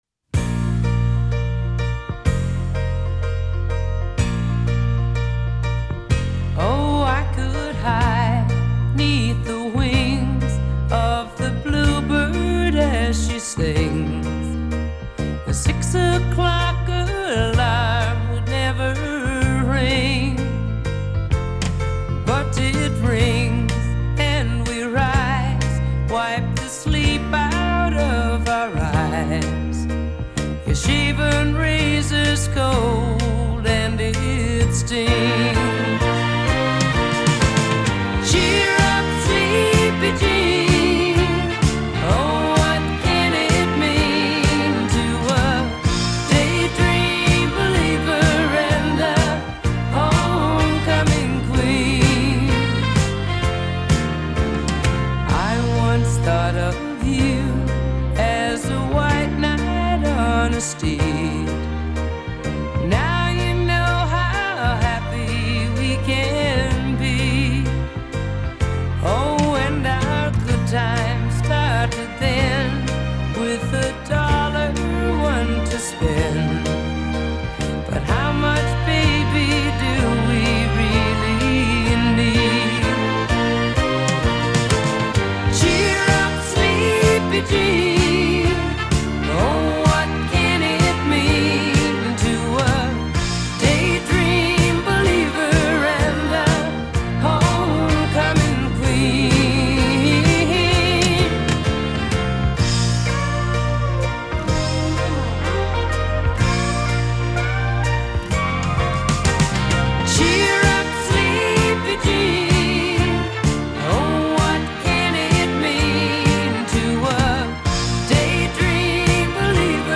平稳的曲调中略带一些伤感，但随着自己阅历的